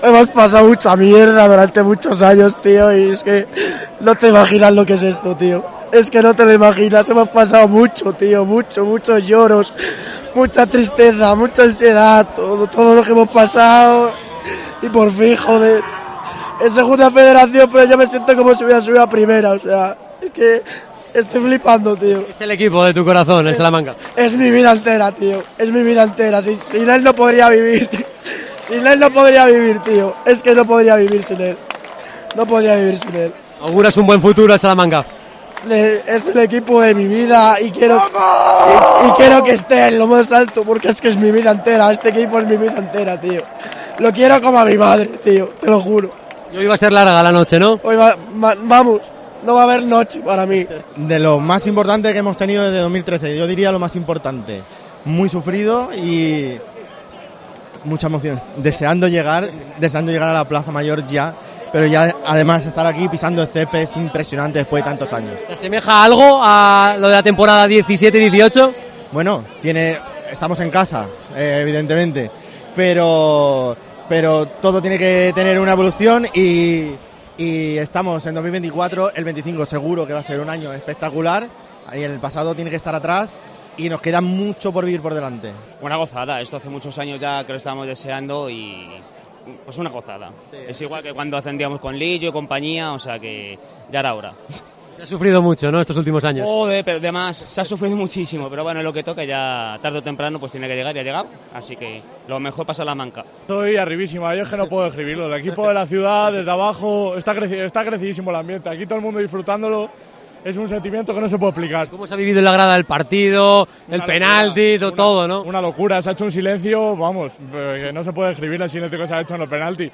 La afición del Salamanca en COPE tras el ascenso